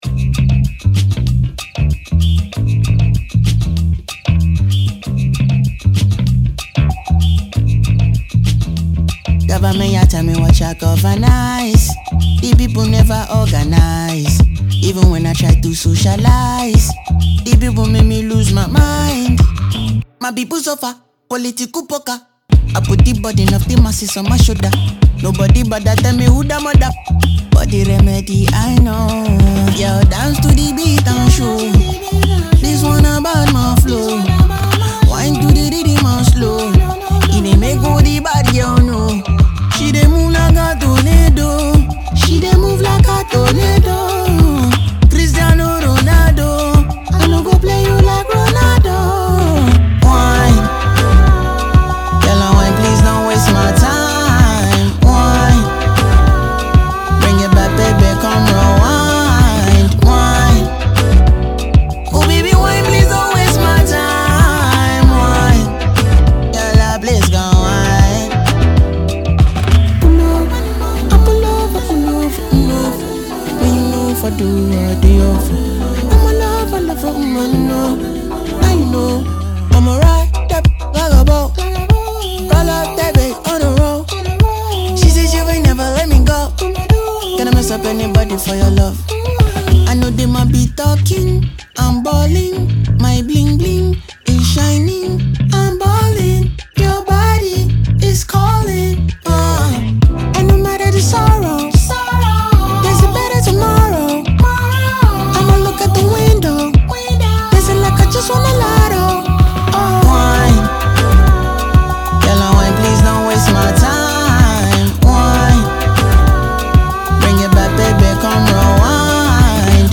dance single